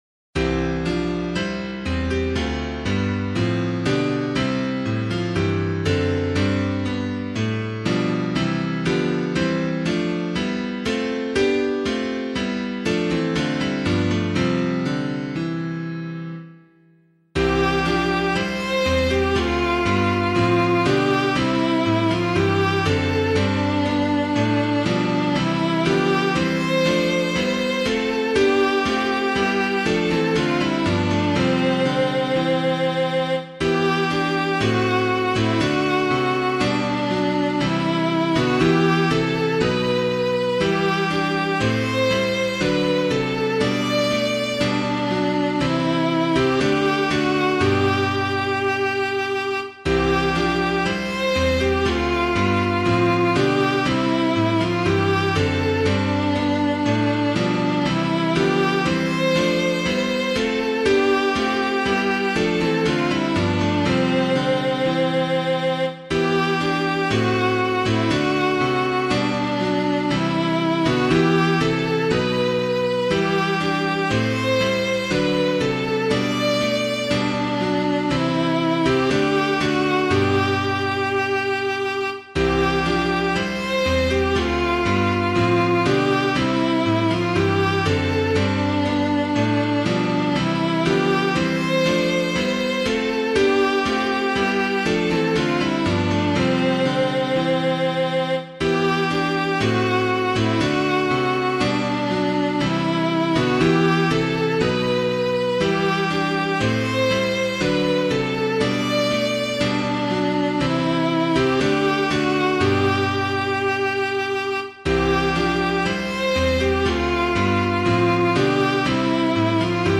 Hymn suitable for Catholic liturgy.
Lift High the Cross the Love of Christ Proclaim [Kitchin + Newbolt - CRUCIFER] - piano.mp3